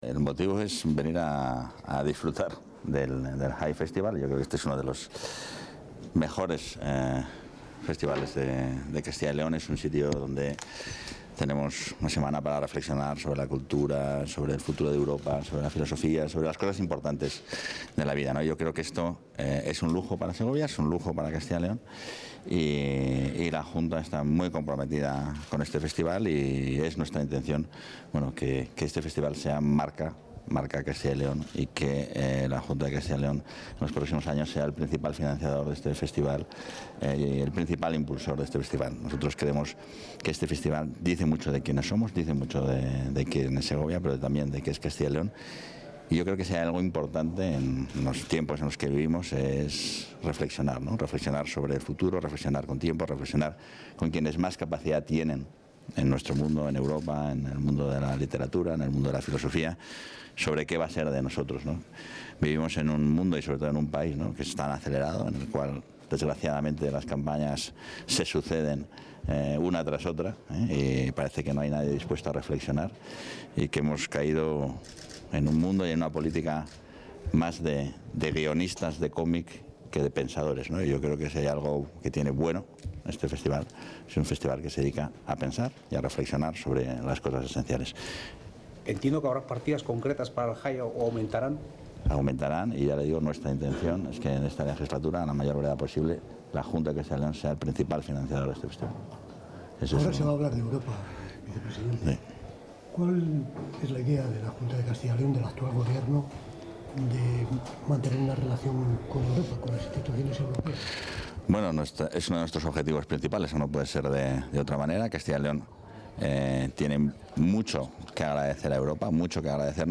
Atención a medios.